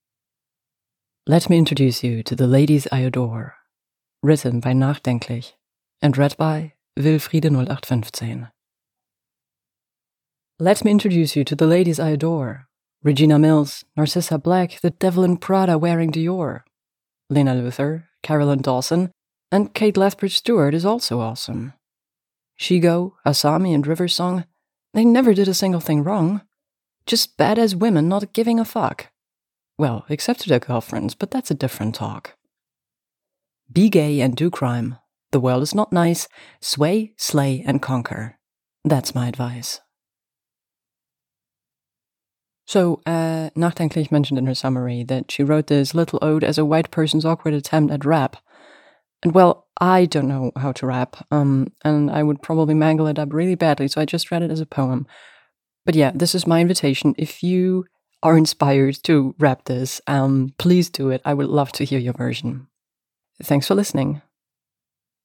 info|gen, info|poetry